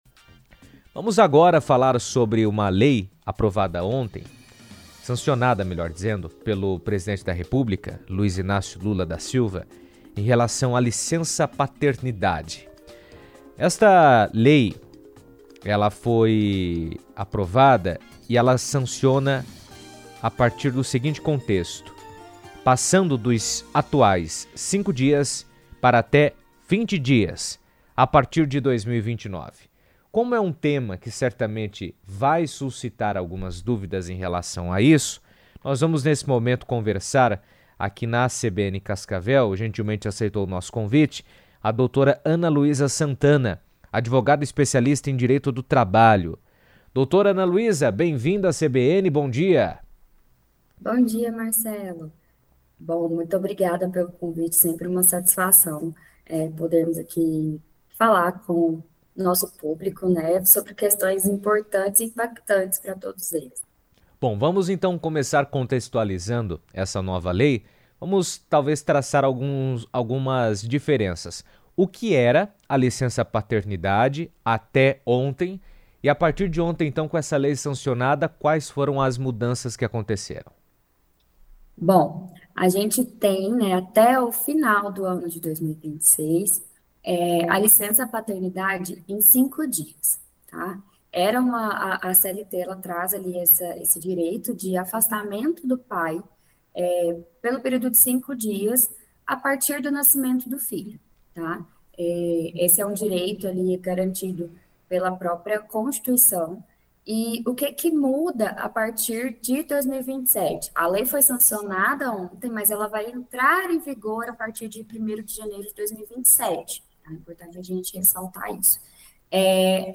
Foi sancionada nesta terça-feira (31) a lei que amplia de cinco para até 20 dias a licença-paternidade no Brasil, com aplicação gradual até 2029, e prevê a criação do salário-paternidade durante o período de afastamento. Durante entrevista na CBN